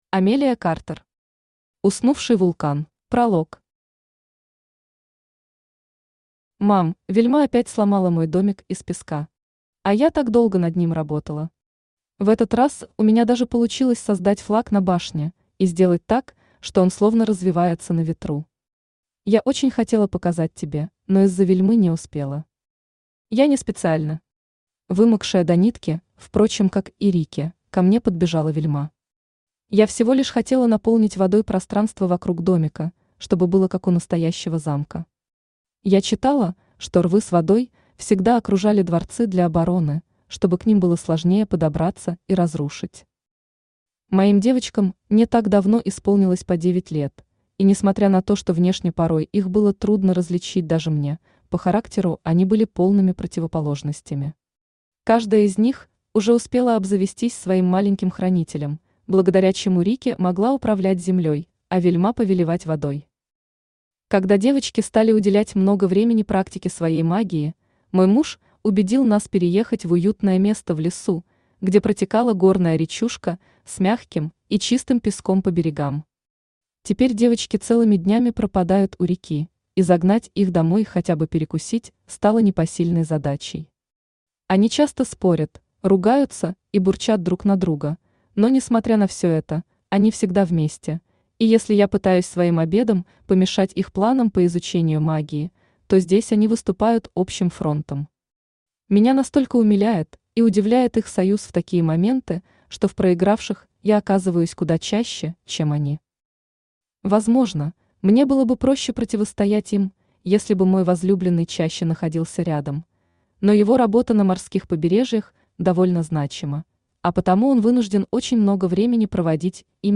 Aудиокнига Уснувший вулкан Автор Амелия Картер Читает аудиокнигу Авточтец ЛитРес.